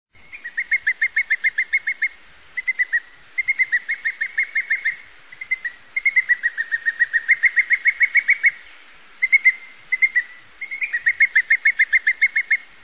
Canto del águila calzada
canto-aguila-calzada.mp3